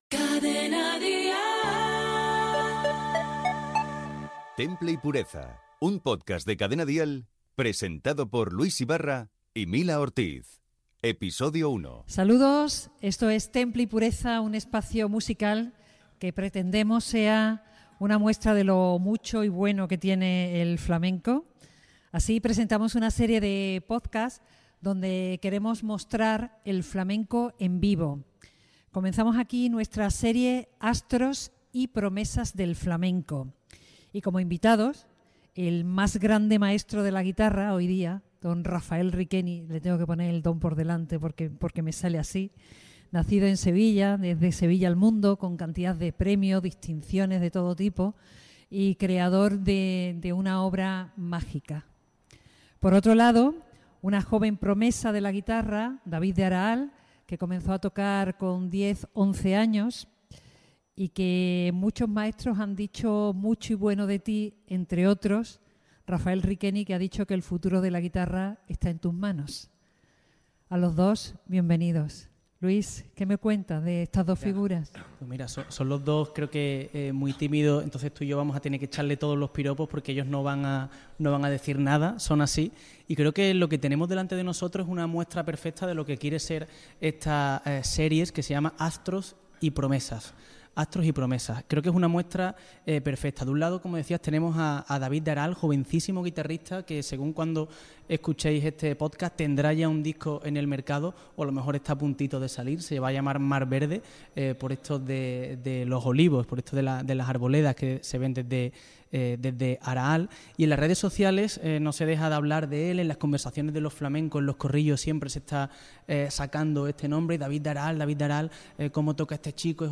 Indicatiu de la cadena, careta, presentacíó del programa i dels invitats
Gènere radiofònic Musical